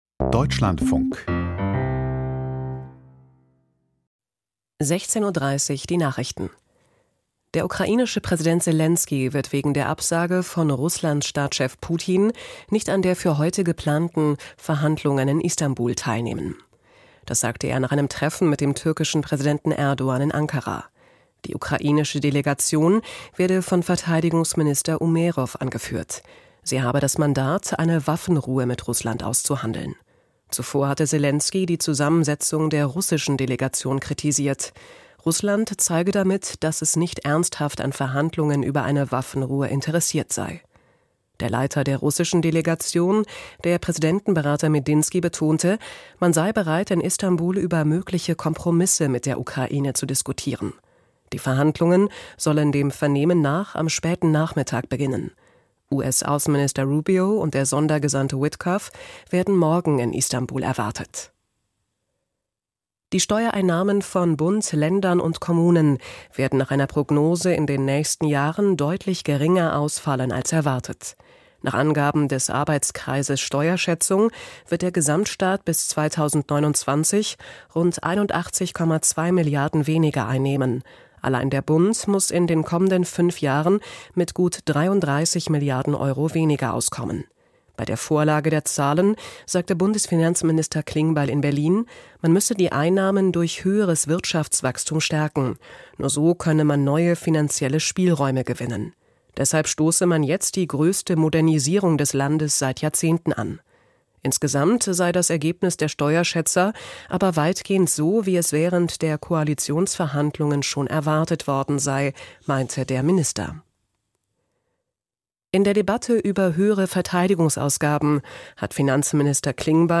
Die Nachrichten vom 15.05.2025, 16:30 Uhr
Aus der Deutschlandfunk-Nachrichtenredaktion.